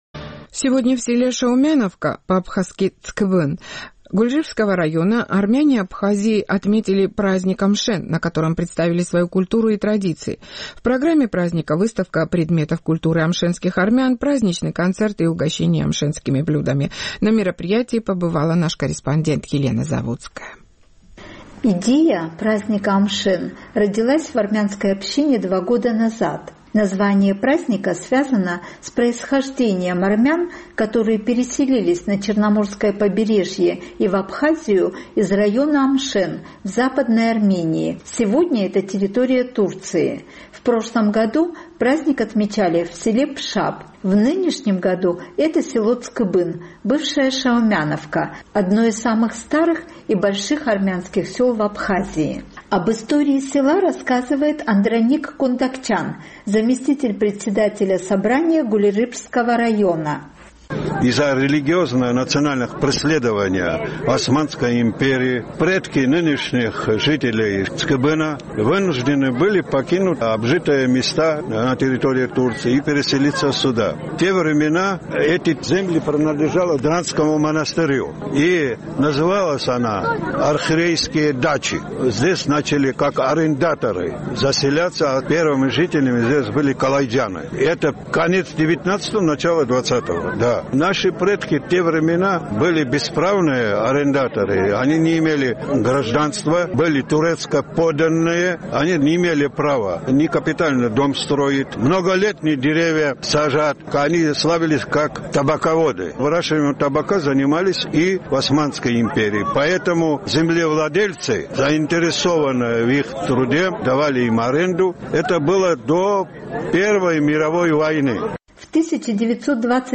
Детские хореографические ансамбли армянских школ исполнили амшенские танцы
Люди встречались, обнимались, со всех сторон звучало: «Барев дзес!», «Здравствуйте!»